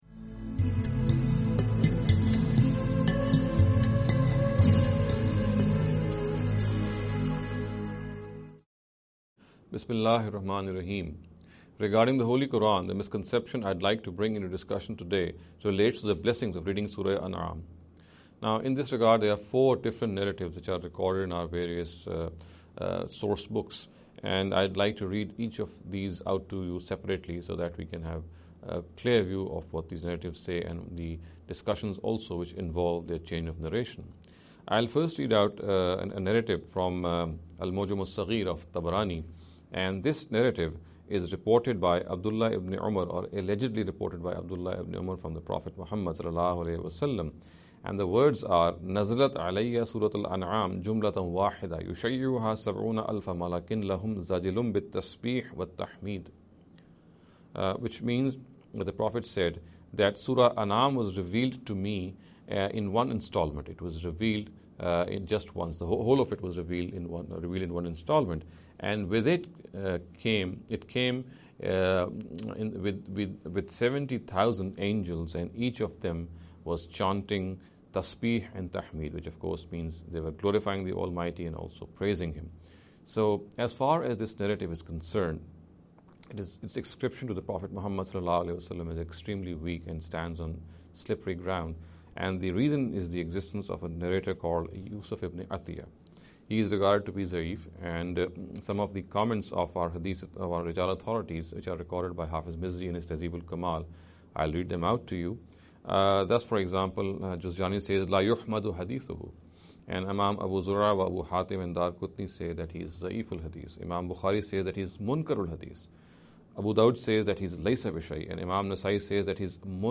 This lecture series will deal with some misconception regarding the Holy Quran.